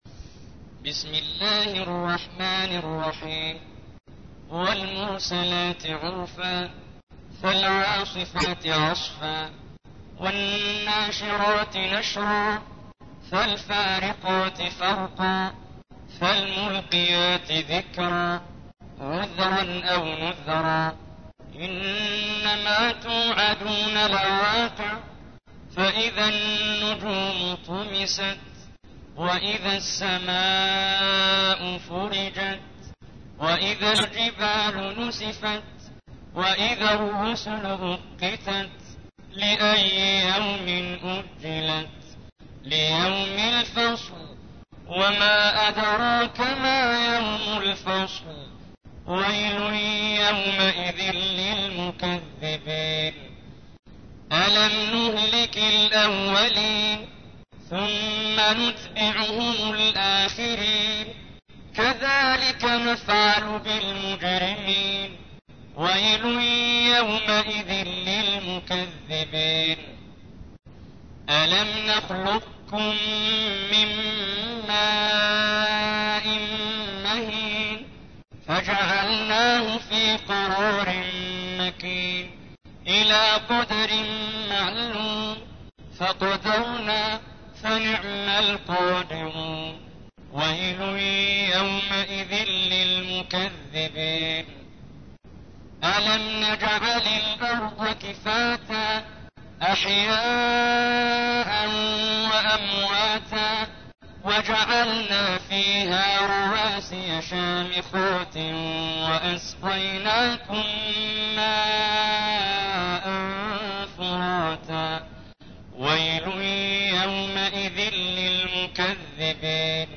تحميل : 77. سورة المرسلات / القارئ محمد جبريل / القرآن الكريم / موقع يا حسين